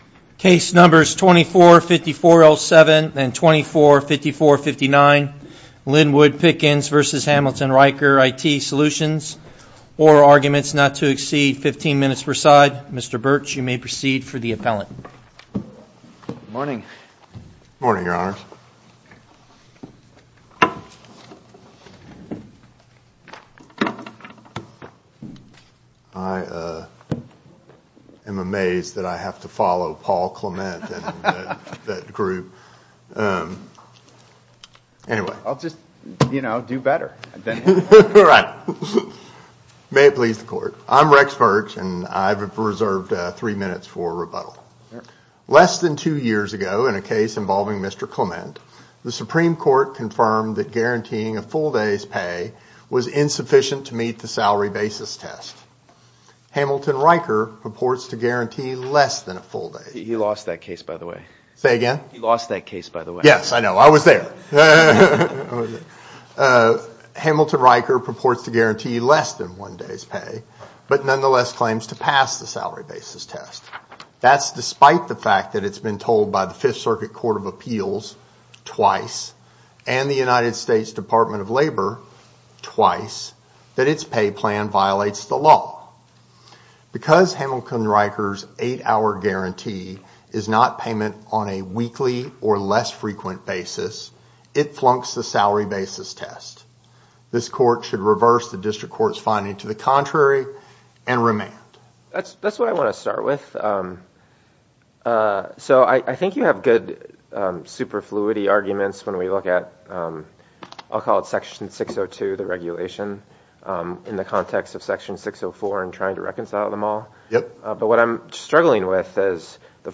A chronological podcast of oral arguments with improved files and meta data.